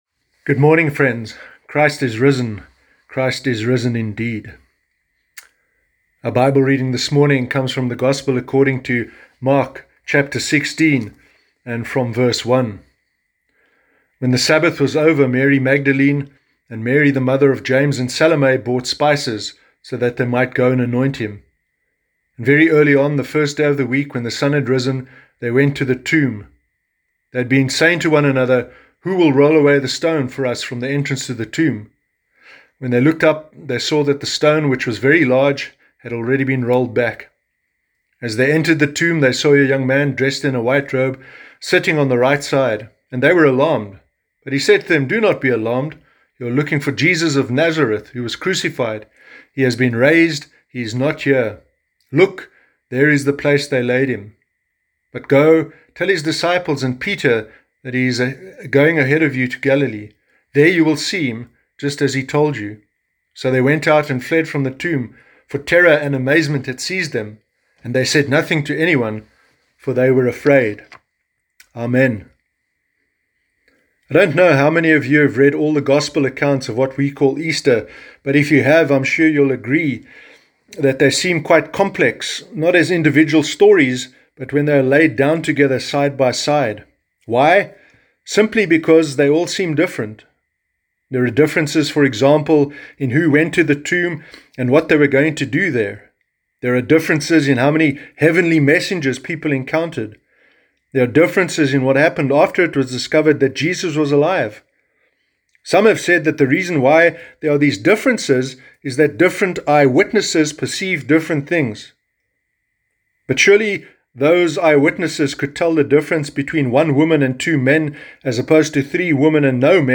Sermon Easter Sunday 12 April 2020